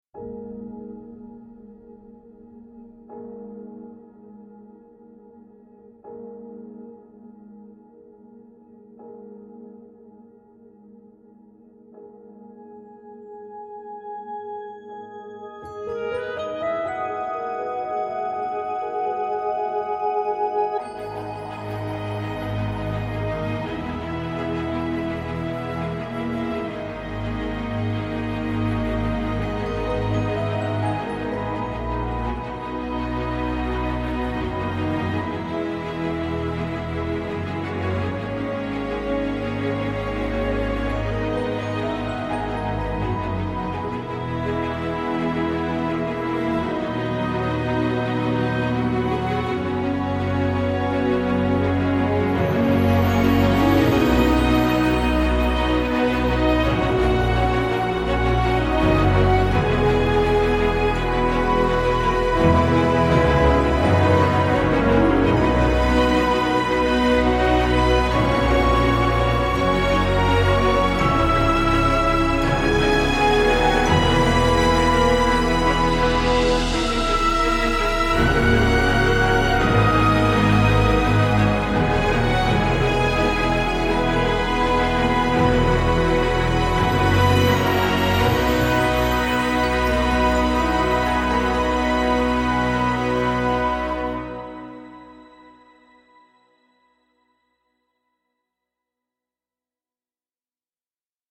pour chœur et orchestre